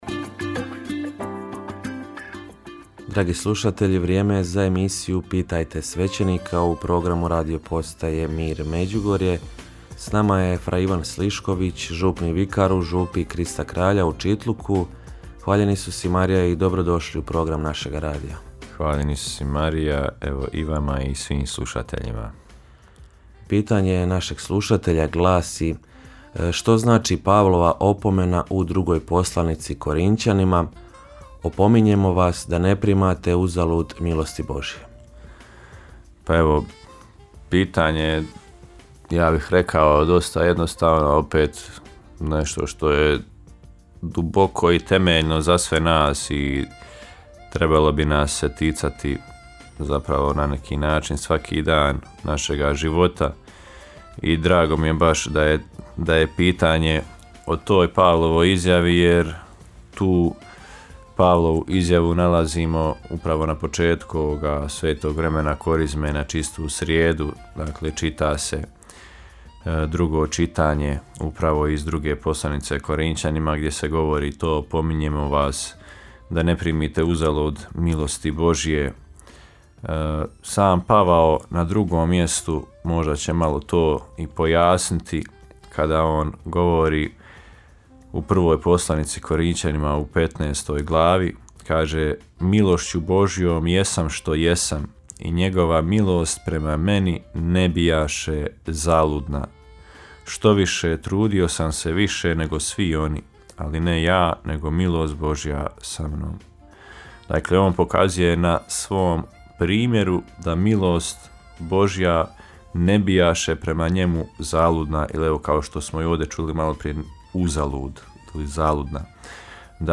Rubrika ‘Pitajte svećenika’ u programu Radiopostaje Mir Međugorje je ponedjeljkom od 8 sati i 20 minuta, te u reprizi ponedjeljkom navečer u 20 sati. U njoj na pitanja slušatelja odgovaraju svećenici, suradnici Radiopostaje Mir Međugorje.